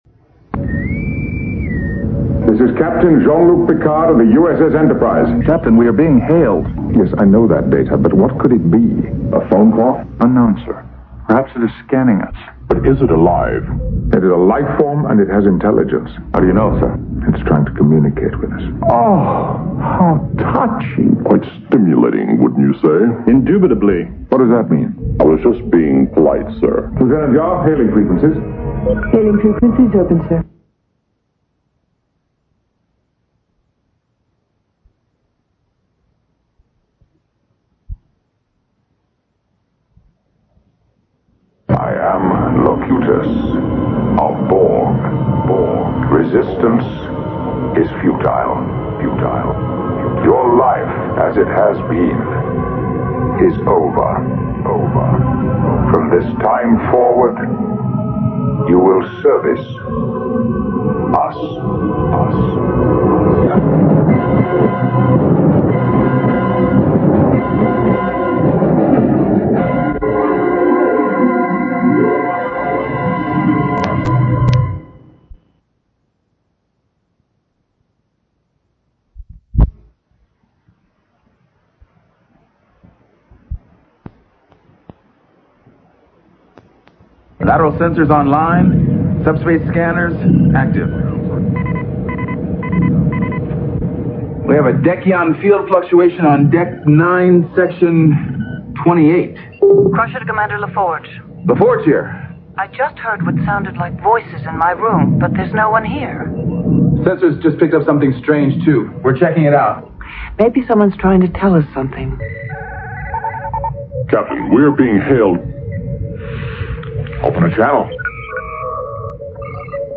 Enjoy 10 minutes of Star Trek Sound Effects from a extremely rare cassette from a Star Trek Convention. Because this was a custom made tape which was never published commercially, it is very rare and hard to find.